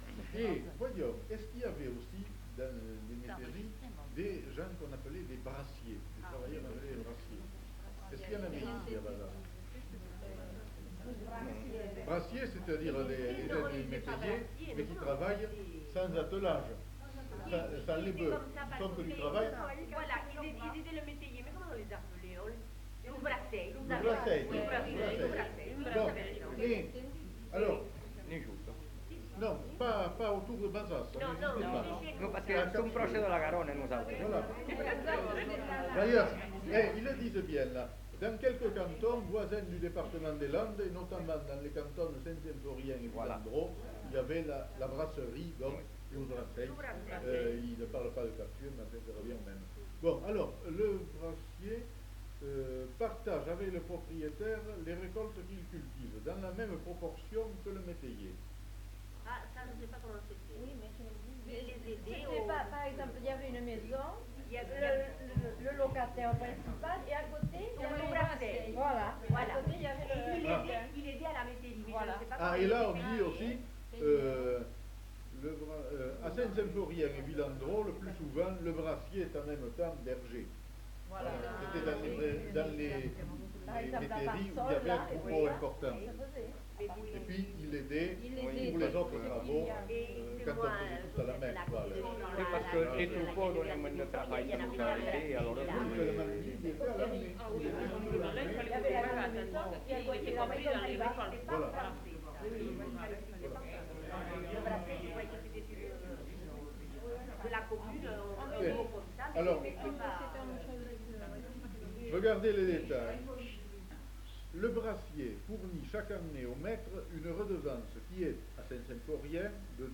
Lieu : Bazas
Genre : témoignage thématique